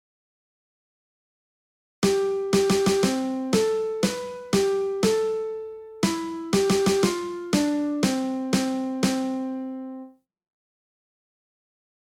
これを先ほどの「くるみ割り人形　行進曲」調にリズムを変えてみたいと思います。